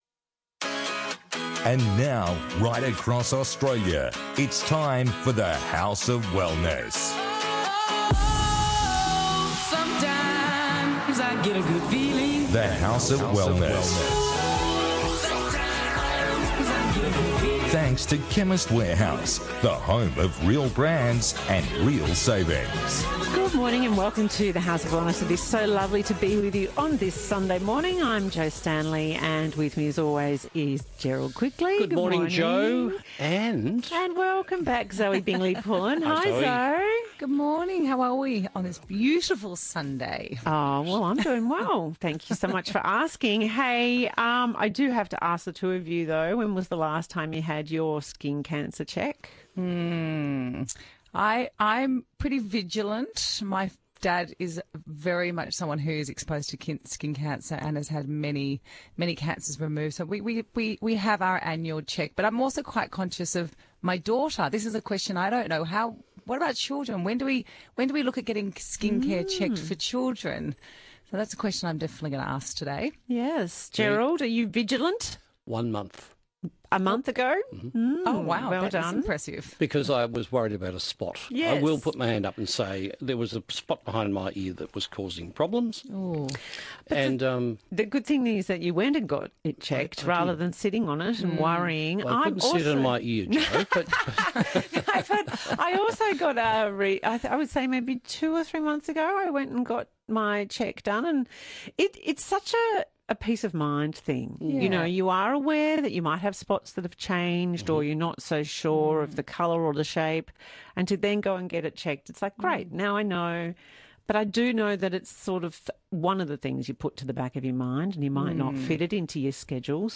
Get well, stay well, live well and look fabulous with The House of Wellness radio show.